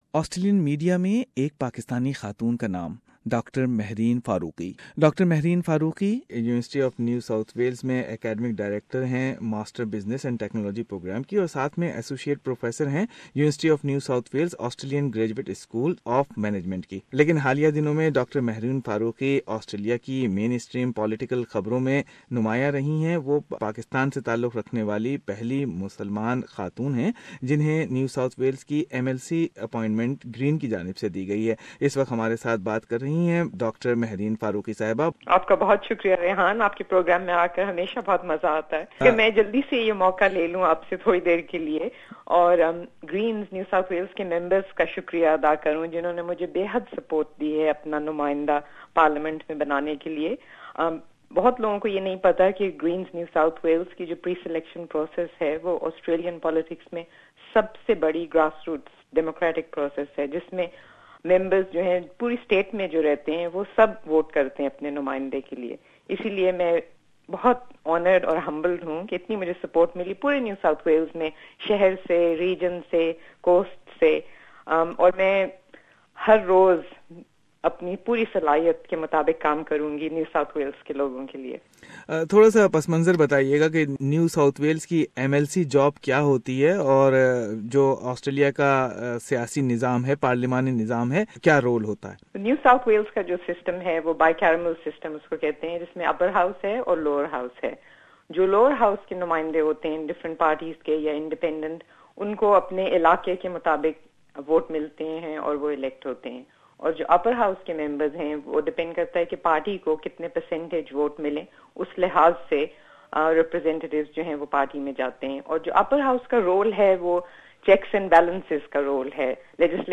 A PAKISTANI-born migrant is set to become the first Muslim woman elected to an Australian parliament, after the NSW Greens chose Mehreen Faruqi to fill a state upper house vacancy. Interview of Dr Mehreen Faruqi who is Academic Director of the Master of Business & Technology Program and Associate Professor at the Australian Graduate School of Management, University of NSW.